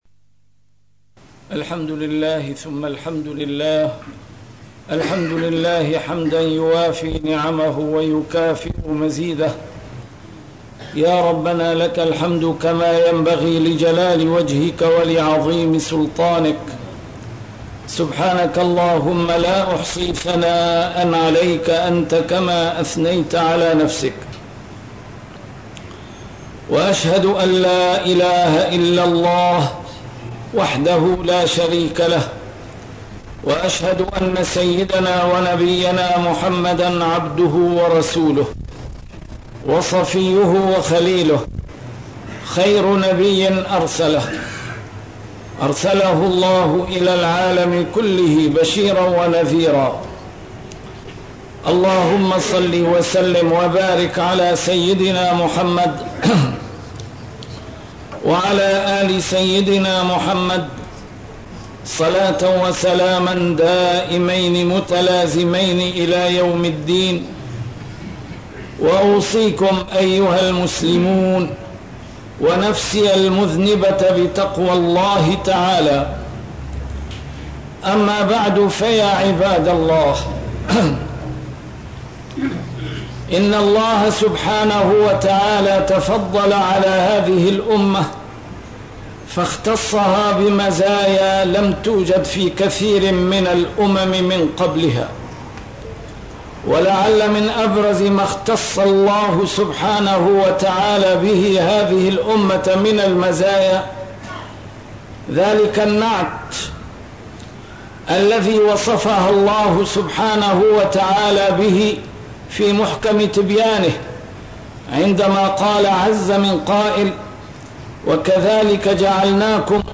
A MARTYR SCHOLAR: IMAM MUHAMMAD SAEED RAMADAN AL-BOUTI - الخطب - الشام تنفي التطرف كما نفته من قبل